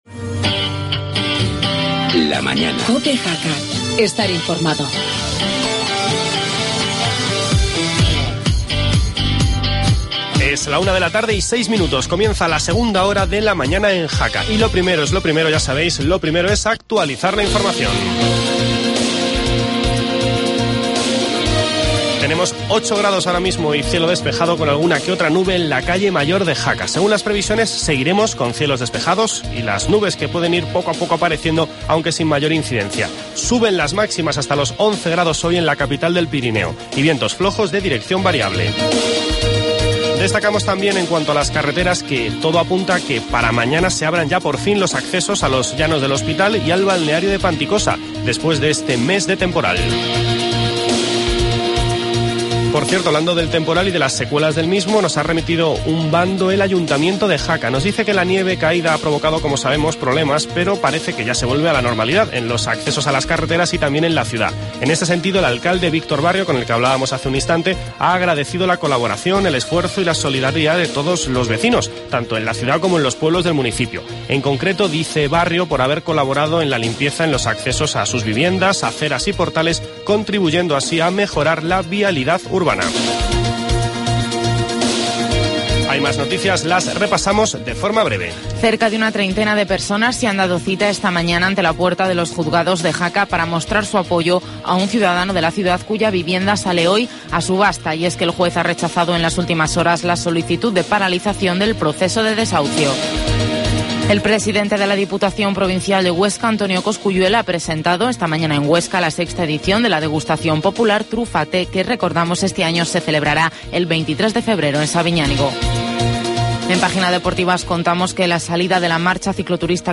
Hablamos con el concejal de deportes del Ayuntamiento, José Manuel Prada